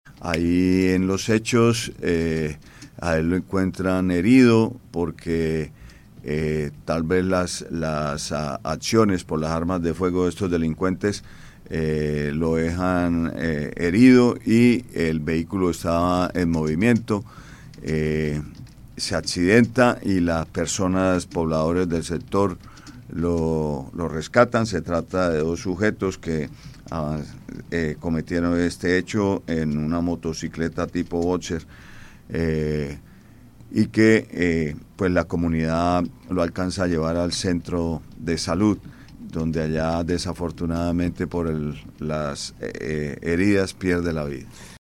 Oscar Hernández, secretario del interior de Santander